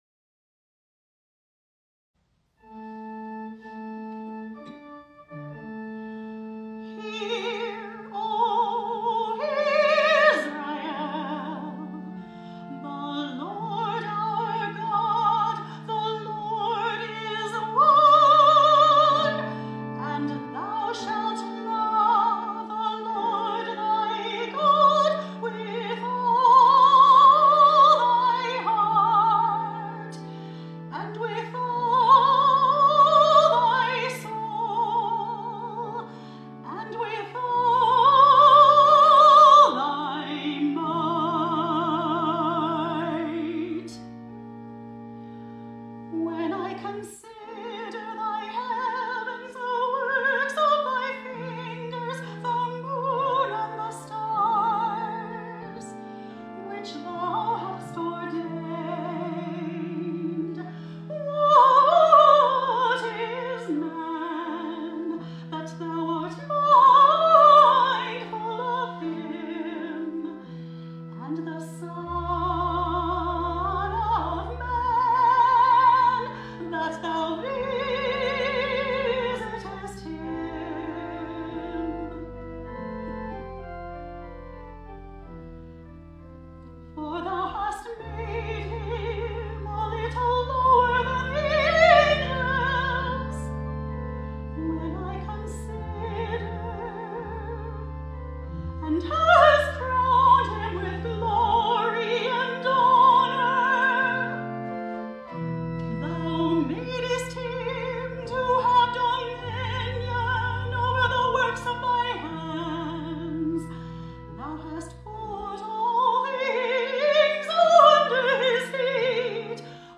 Latest Solos